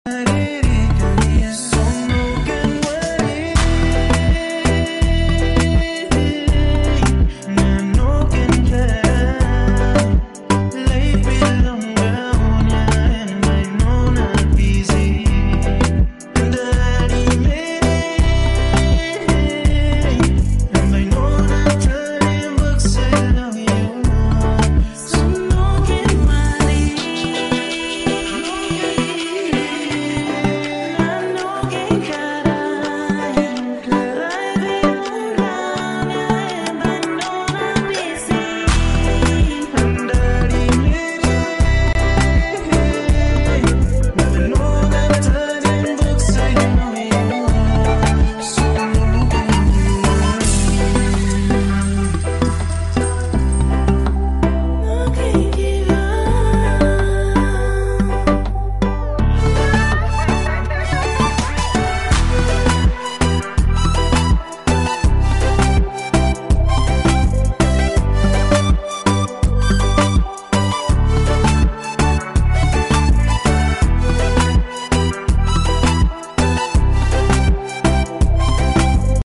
Moombah Chill Remix